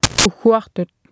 Speech synthesis Martha to computer or mobile phone